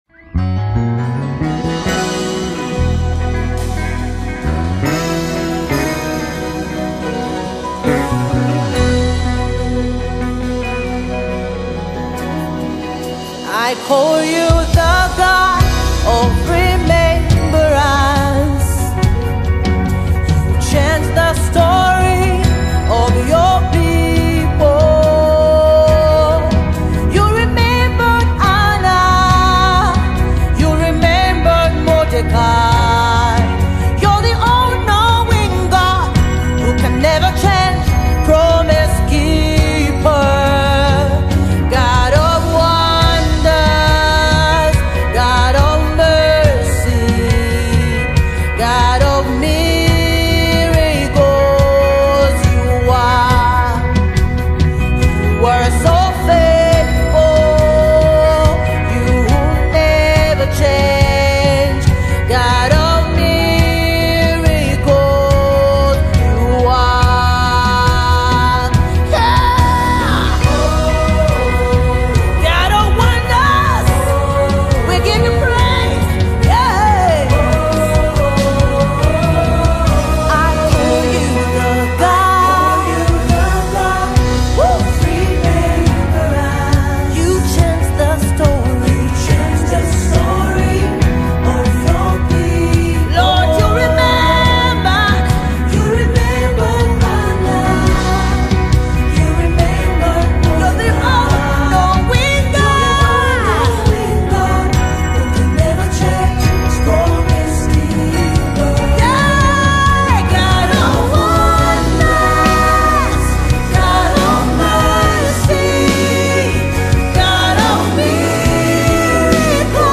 Zambia's beloved gospel artist
delivery is both comforting and inspiring